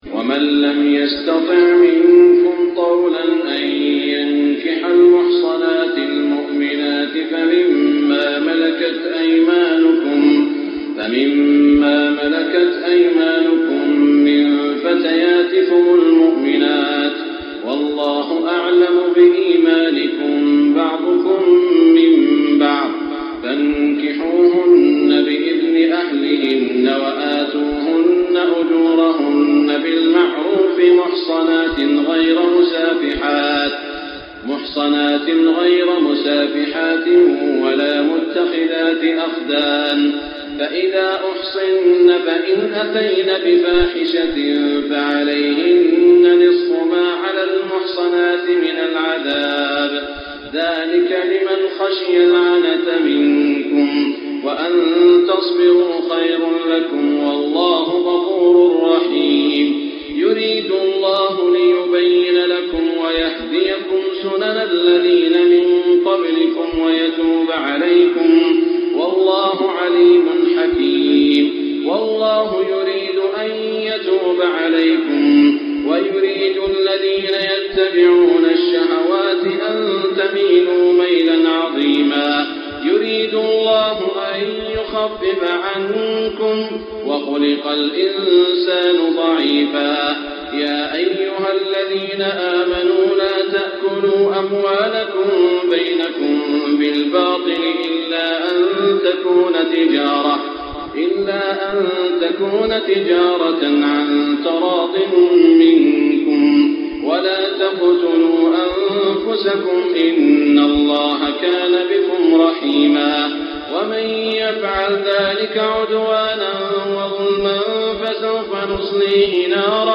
تهجد ليلة 25 رمضان 1425هـ من سورة النساء (25-99) Tahajjud 25 st night Ramadan 1425H from Surah An-Nisaa > تراويح الحرم المكي عام 1425 🕋 > التراويح - تلاوات الحرمين